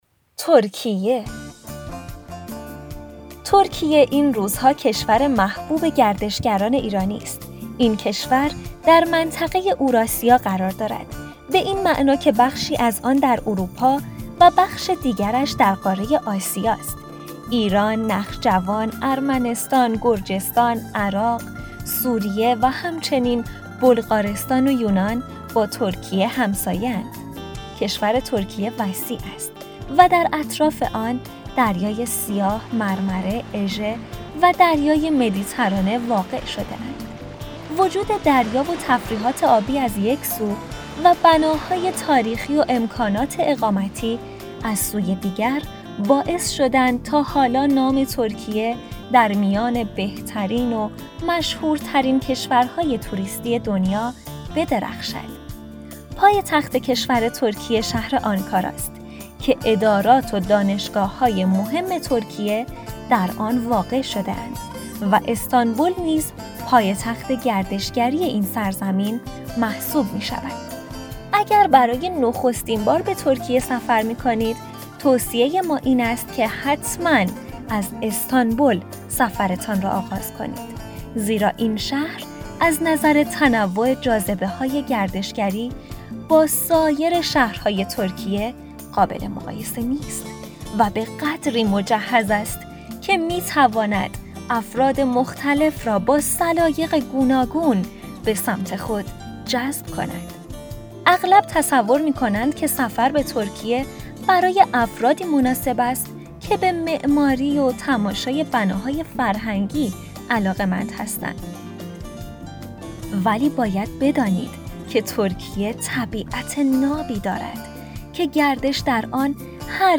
ترکیه | راهنمای صوتی ترکیه | رادیو فاینداتور